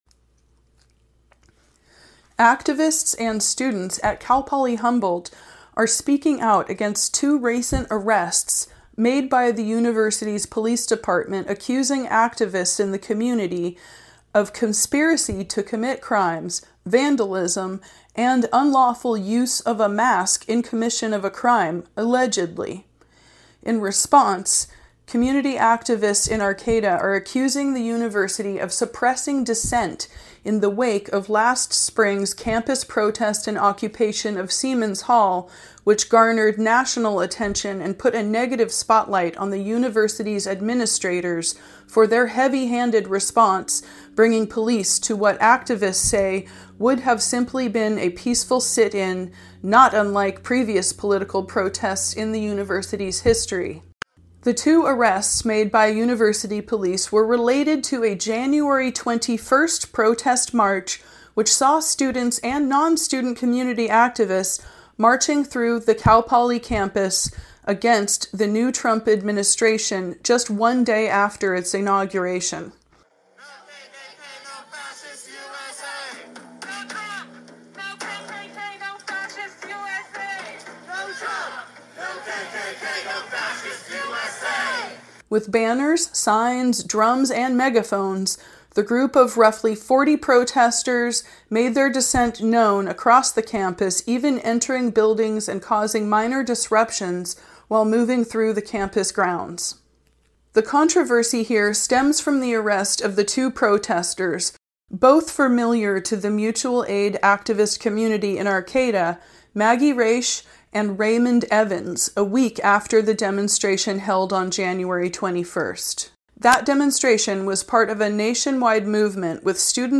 Freelance reporter